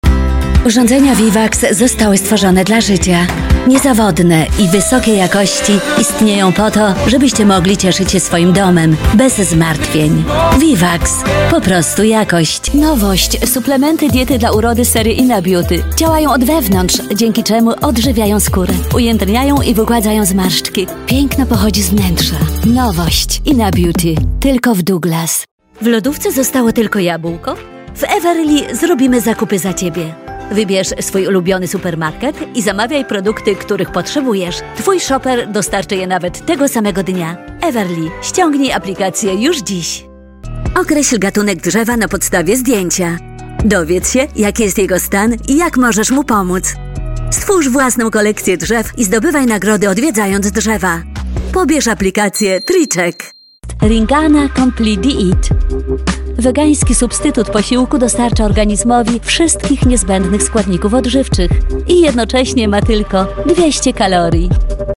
Polnisch, Deutsch (mit Akzent), Englisch (mit Akzent)
Mix - Werbung ° Polnisch